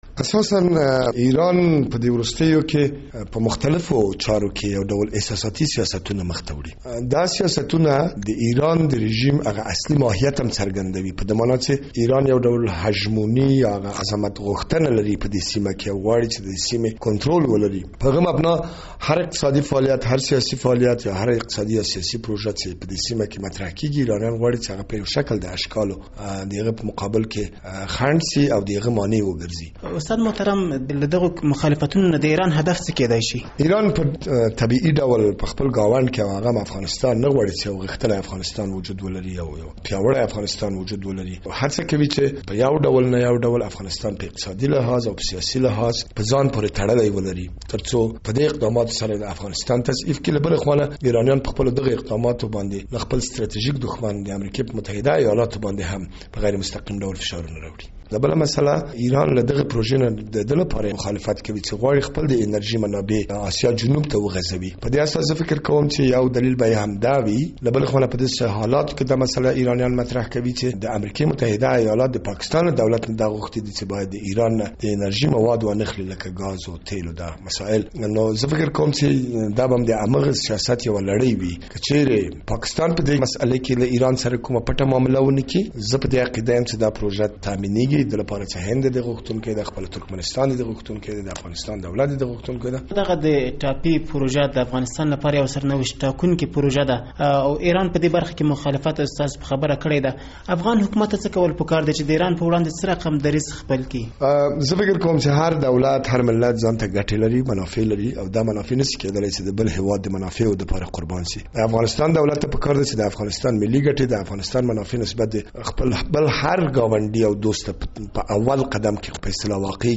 له ستانکزي سره مرکه